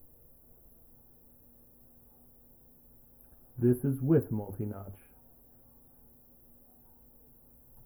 Now with voice.
I can hear a whine, but I can’t find it in the analyze tools enough to manage it.
The voice volume is pretty close.
You also have at least one fan mmmmm in there.